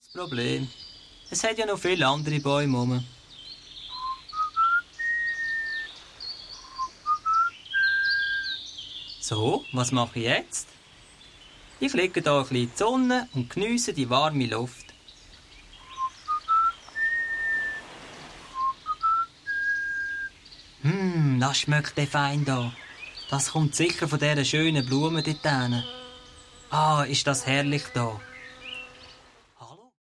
Hörspiel-Album